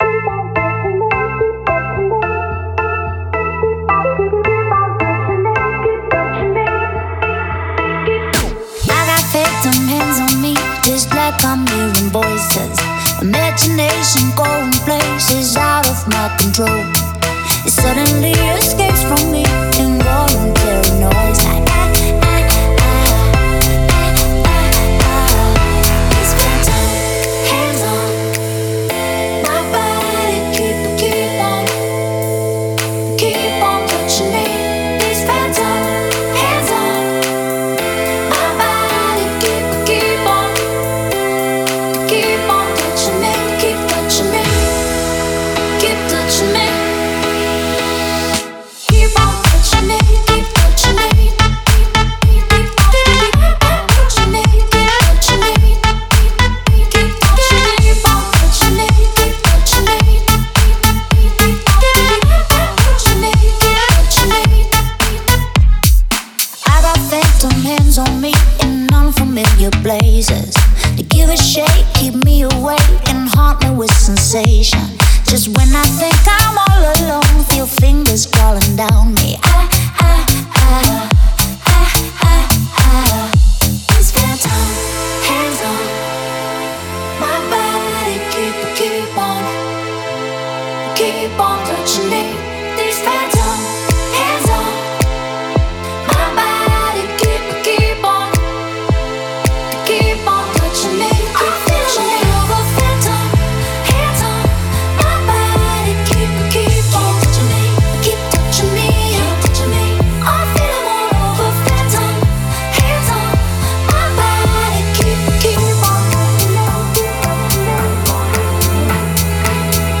захватывающая электронная композиция